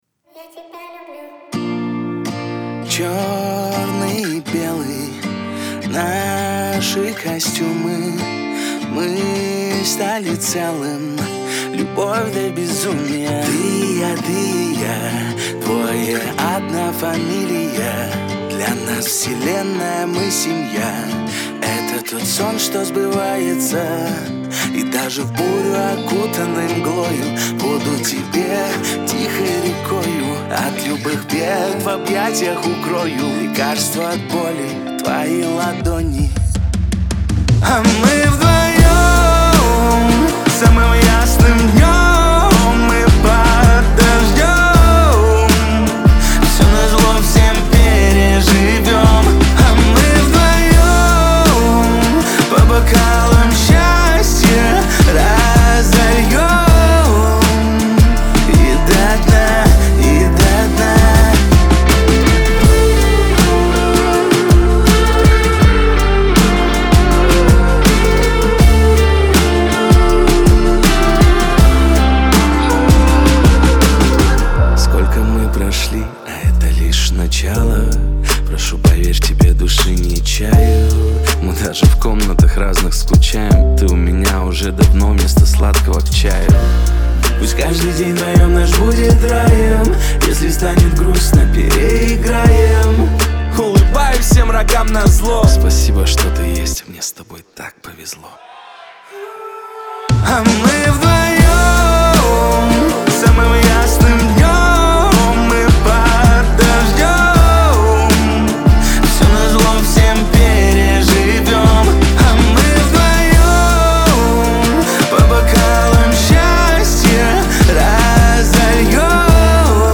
Главная ➣ Жанры ➣ Hip-Hop/Rap. 2025.
Лирика